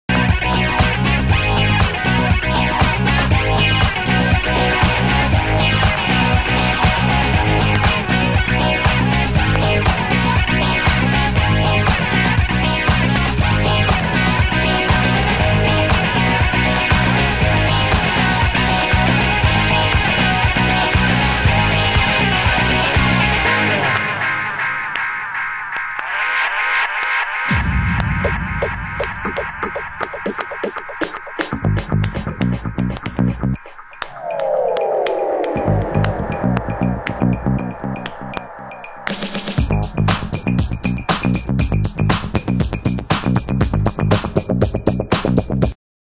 盤質：A面中盤に線の傷ノイズ